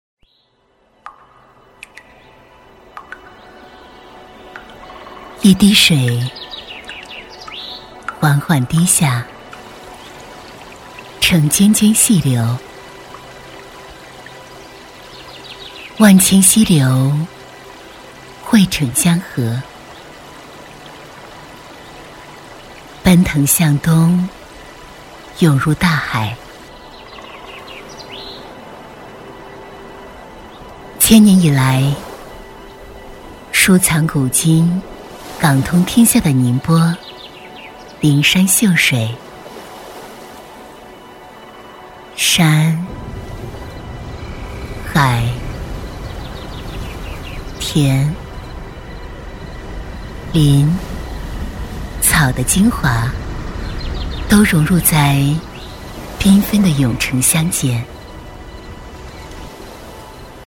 女108-城市宣传《宁波》-文艺 诗意
女108-城市宣传《宁波》-文艺 诗意.mp3